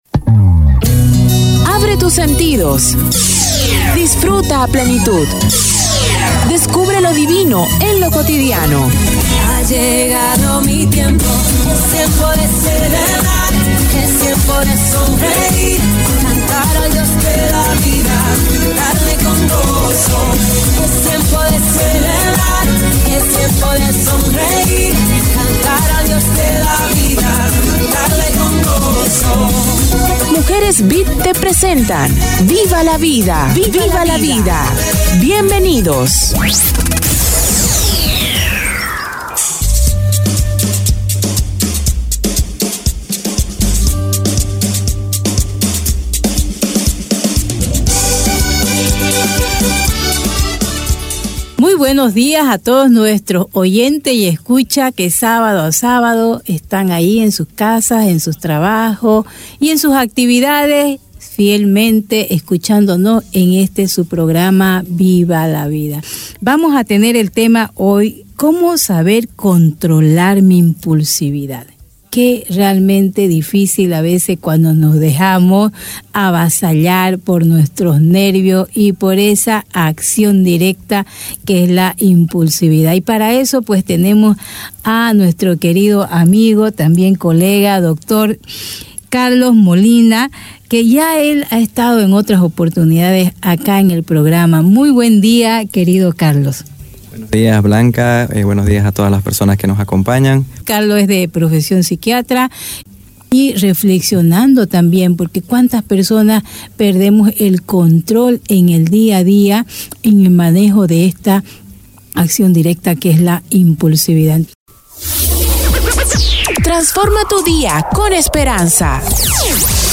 aprendiendo-a-controlarme-dialogo-sobre-la-impulsividad